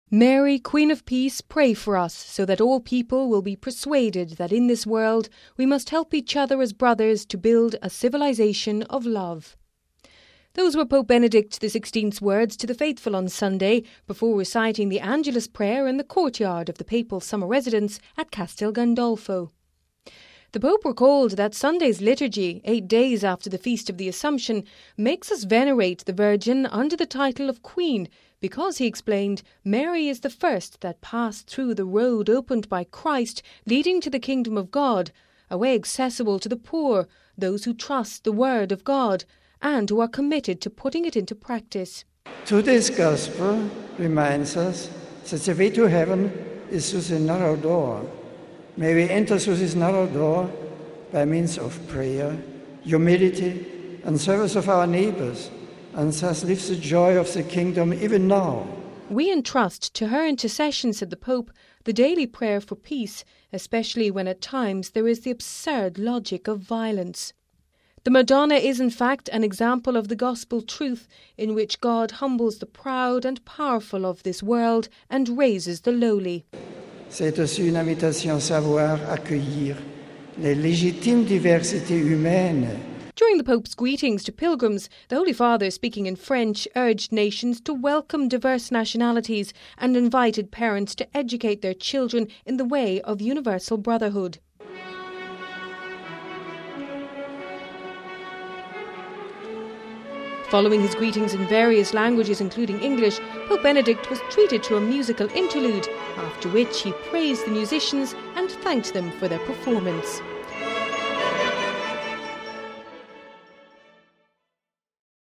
(22 Aug 10 - RV) Pope Benedict during his Angelus at Castelgandolfo Pope Benedict urged people to build a civilisation of love, where there is the absurd logic of violence: RealAudio
Those were Pope Benedict XVI’s words to the faithful, on Sunday, before reciting the Angelus prayer in the Courtyard of the Papal Summer Residence at Castelgandolfo.